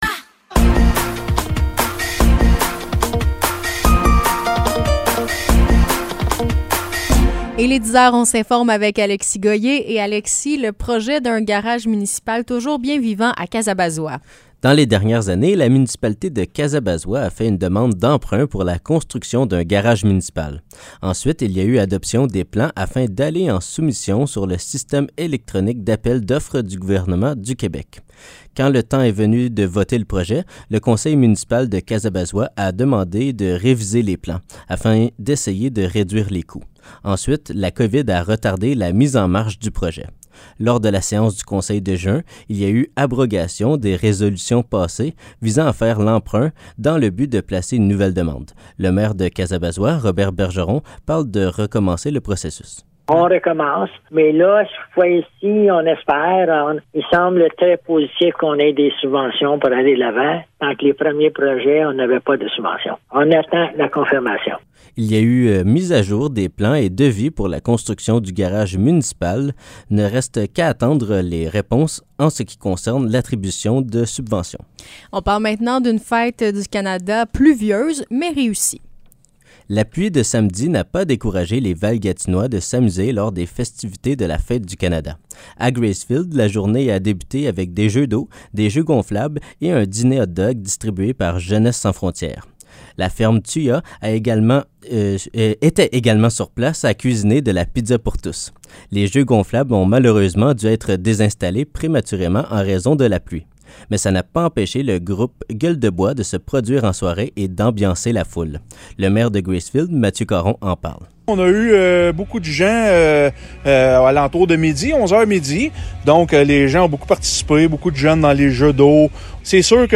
Nouvelles locales - 3 juillet 2023 - 10 h